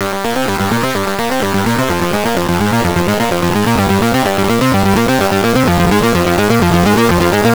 Seriously 8-Bit G 127.wav